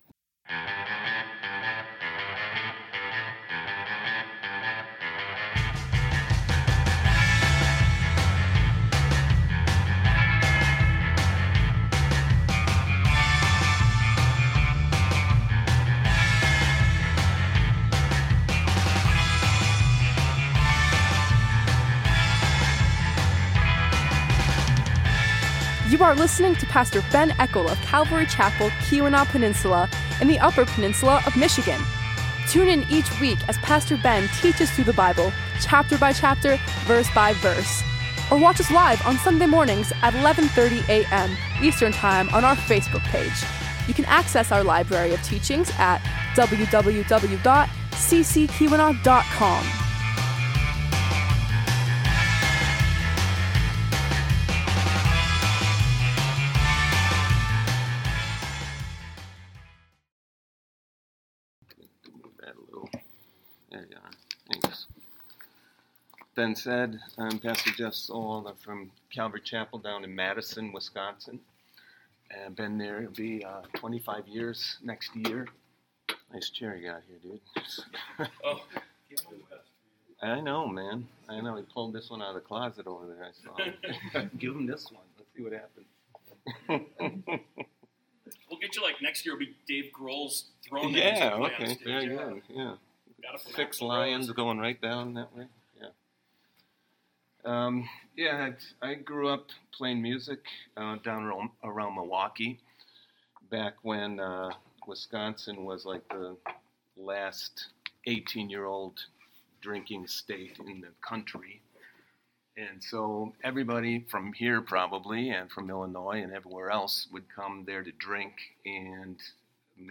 Calvary Chapel Keweenaw Peninsula